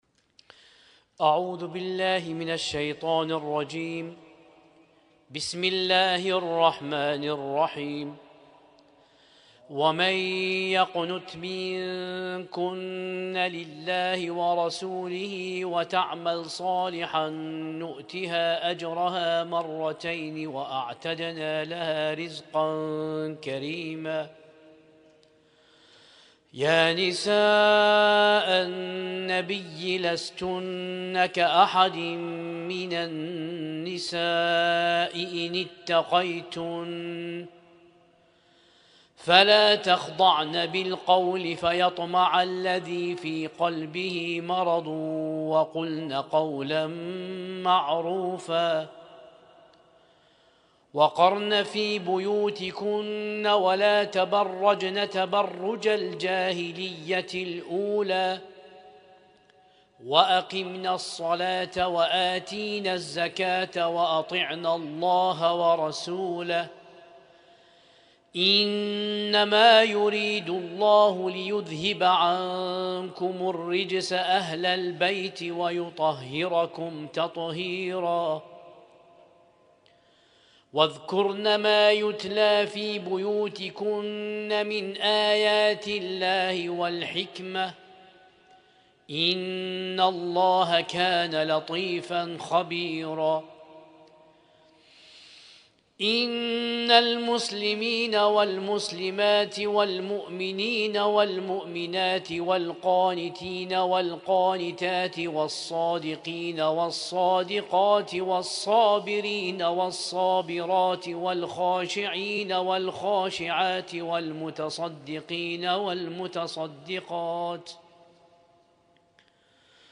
اسم التصنيف: المـكتبة الصــوتيه >> القرآن الكريم >> القرآن الكريم 1447